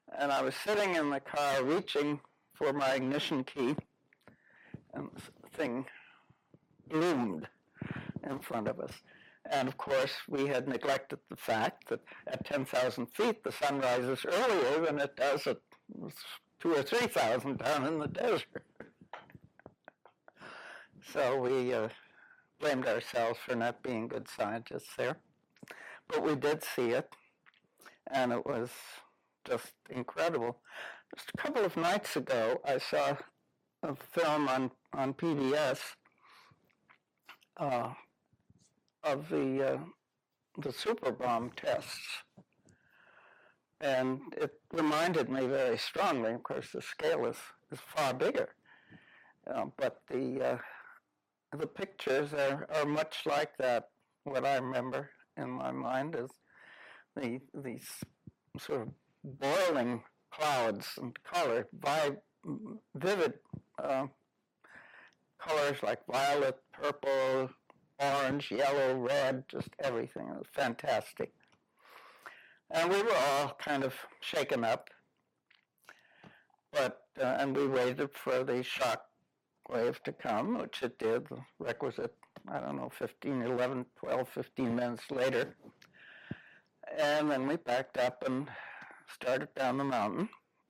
In our search for understanding the role of sound in life with the bomb, we must turn to another form of aural record - that of eyewitness accounts. Here are a few examples, excerpted from longer oral histories: